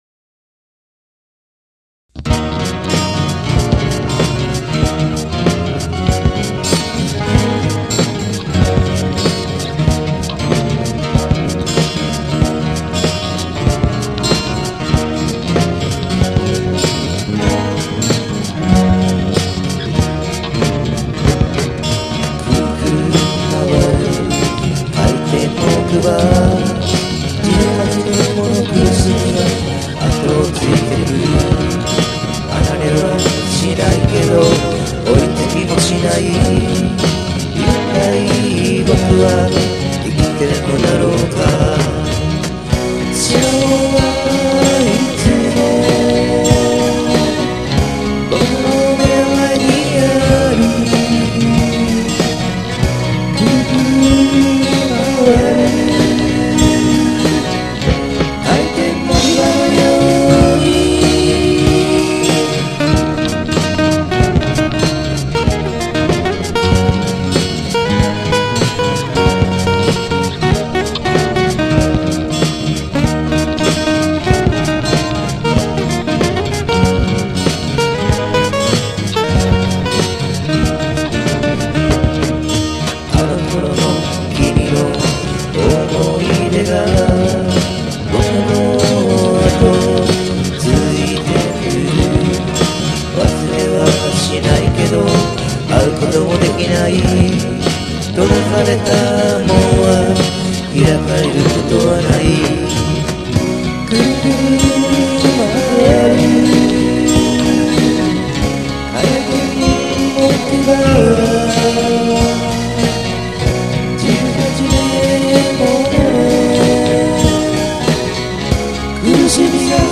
ボーカル＆コーラス
アコギ1  Martin
アコギ2  Ovation
ベースギター   Aria‐proⅡTakeSP Akaei
キーボード  PistonCollage
昔の澄んだ声が、すんだ（終わった）声になってます_|￣|○　ガックリ
１テイクでボーカル、コーラスなど６トラックを合成しております。
それを最終的にMP3に変換しステレオになっております。